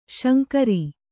♪ śankari